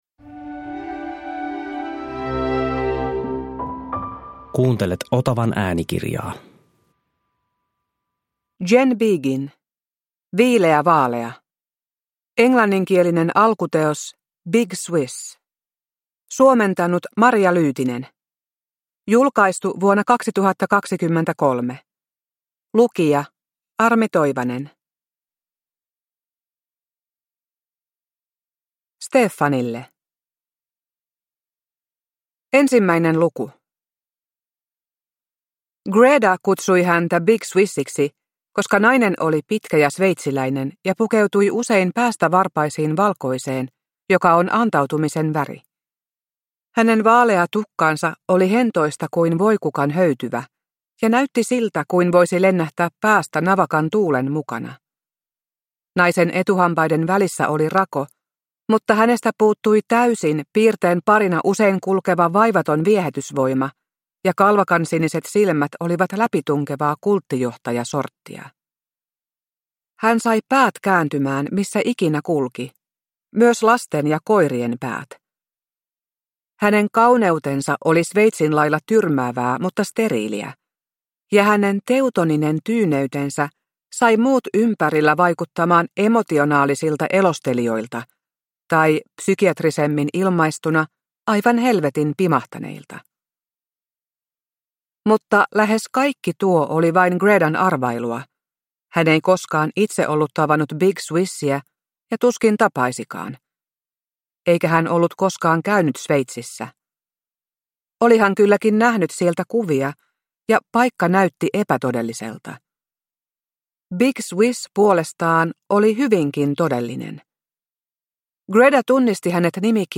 Viileä vaalea – Ljudbok